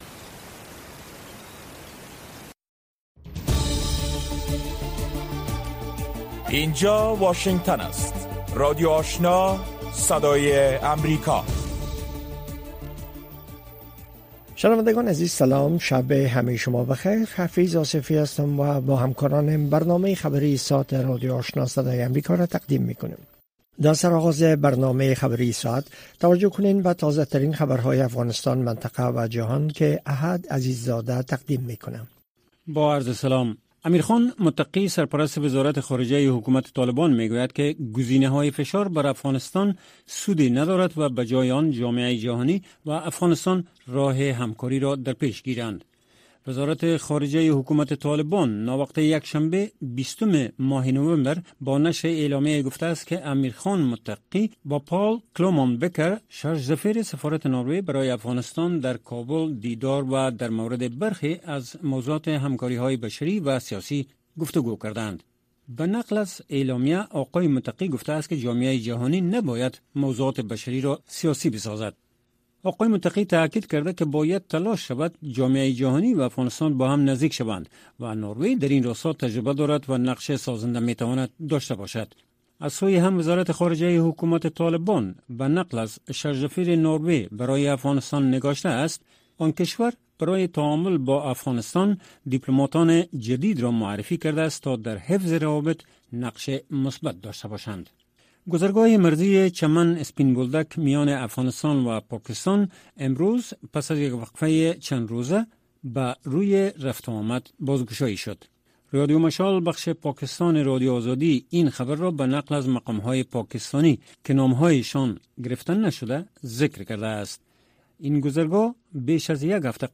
برنامۀ خبری شامگاهی